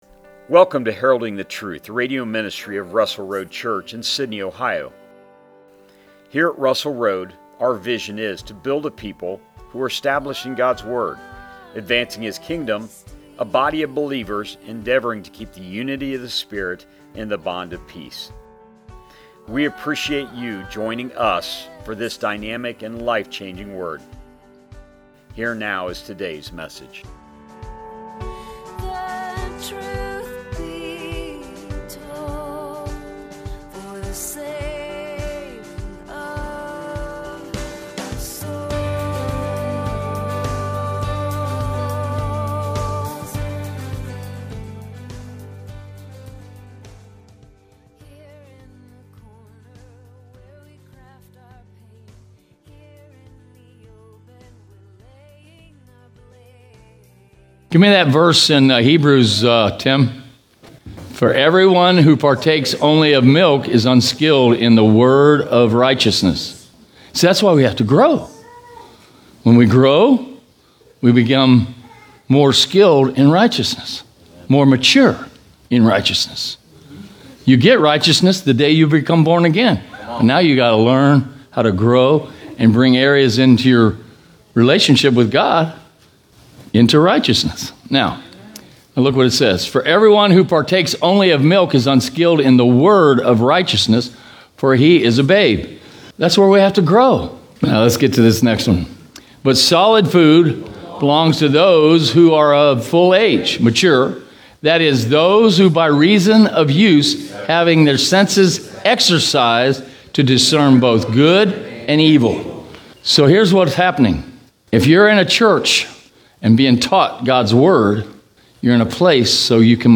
Sermons | Russell Road Church